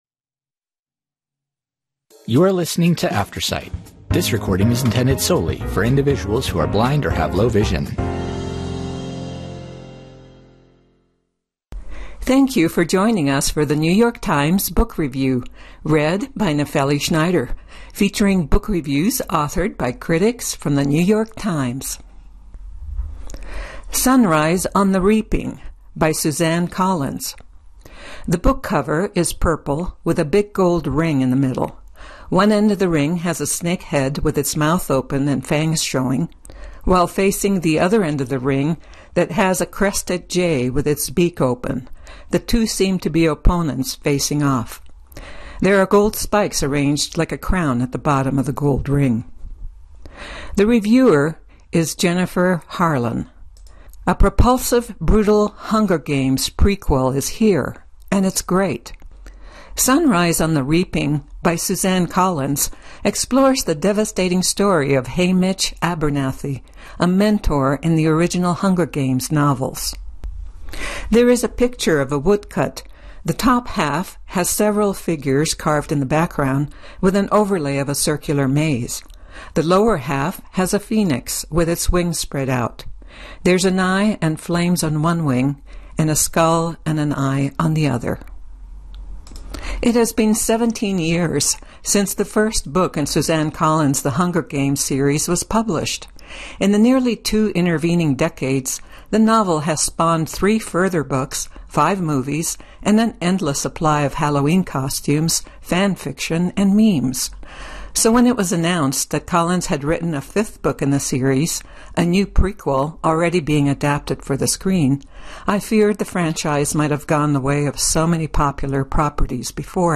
Audio formatted version of the New York Times Book Review, weekly. Aftersight is a media organization that serves individuals with barriers to print.